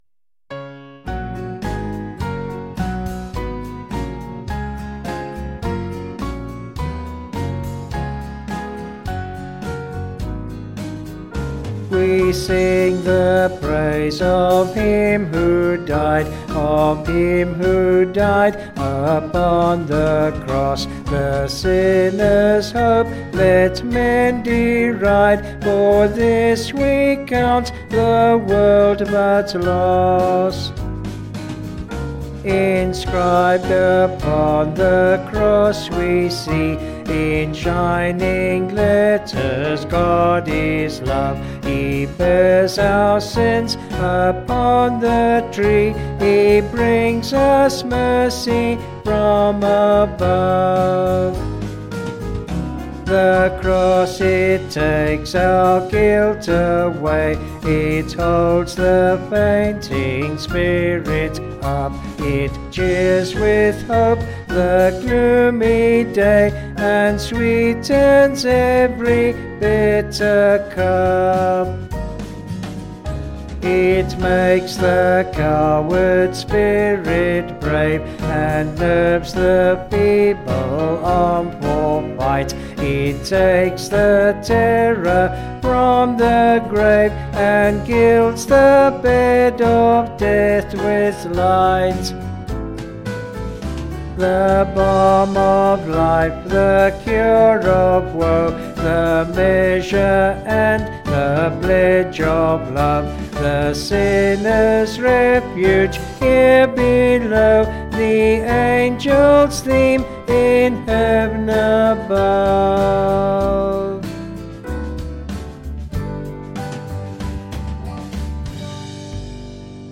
Vocals and Band   263.5kb Sung Lyrics